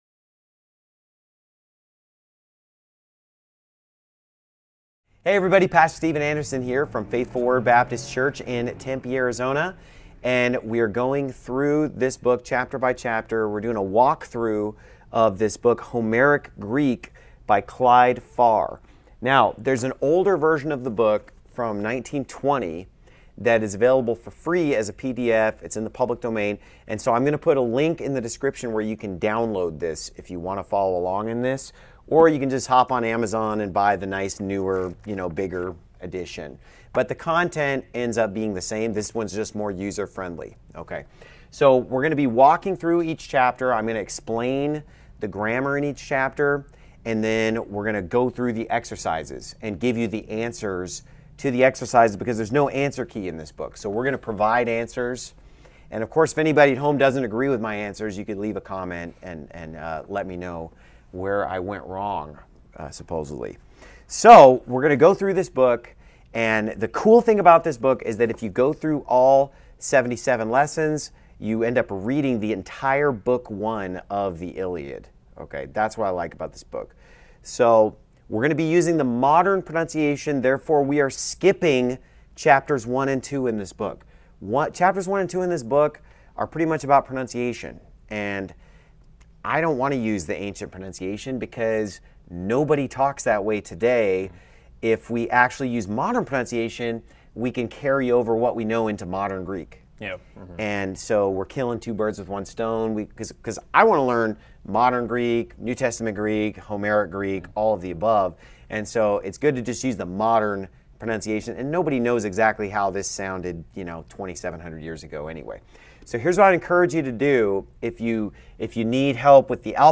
Homeric Greek Lesson 1-3
Homeric_Greek_Lesson_1-3.mp3